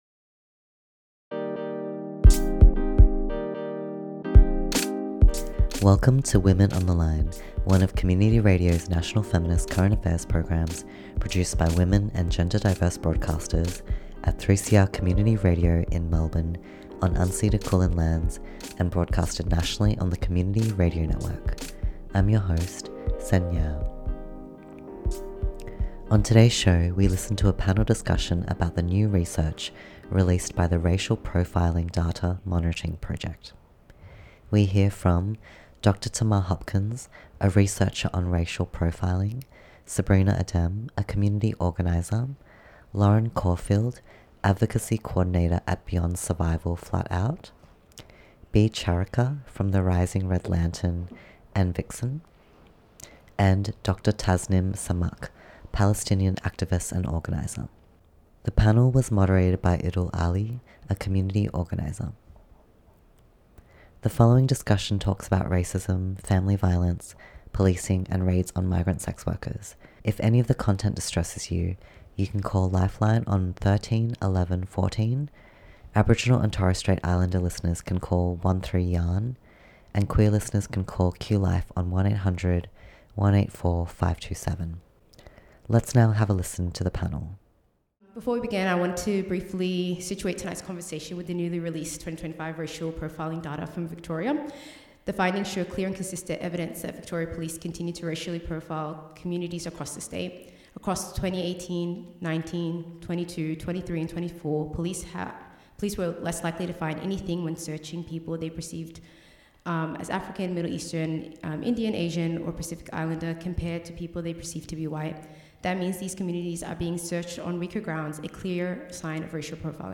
Women on the line went to Melbourne Fringe Festival show "I Am That Woman." The show features five self described non-conforming women who share their experiences.